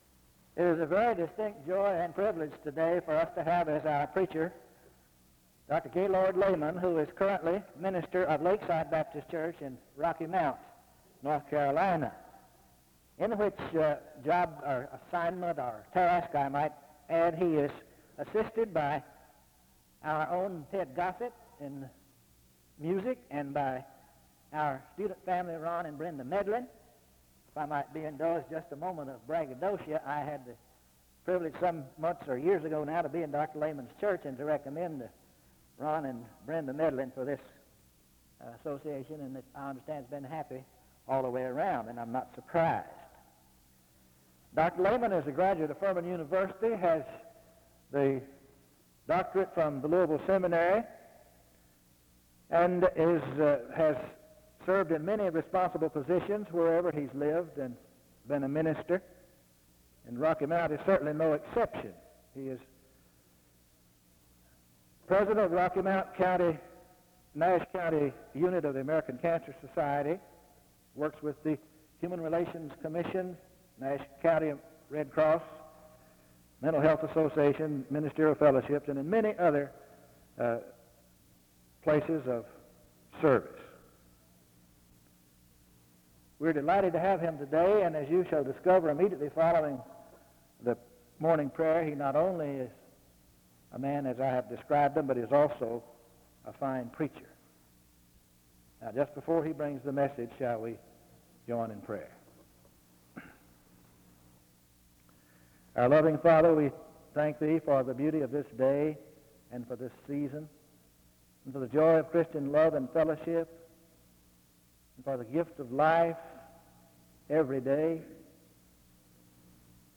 SEBTS Chapel
After which, a prayer is offered (1:45-3:01).